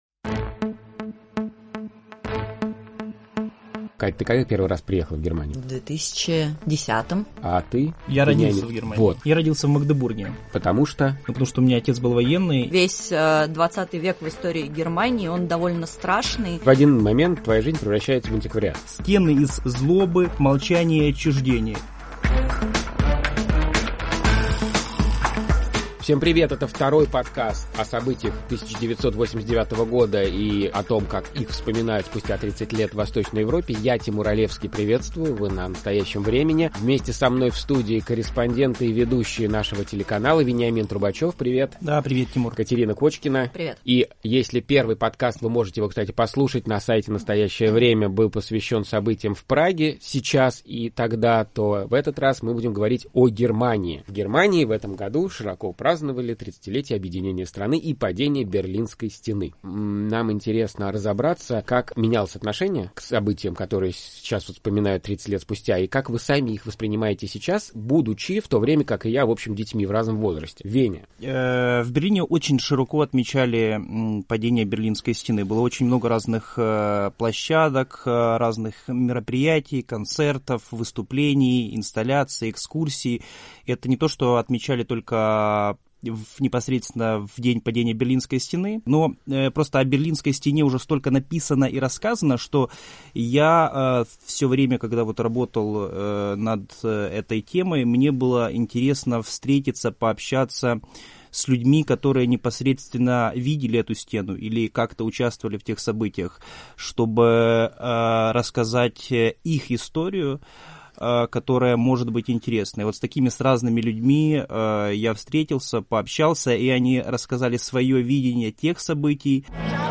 журналисты Настоящего Времени, которые снимали репортажи и работали в прямом эфире во время 30-й годовщины падения Берлинской стены, поговорили об исторической памяти и чувстве вины, и о том, получилось ли тридцать лет назад объединить Германию или оно продолжается до сих пор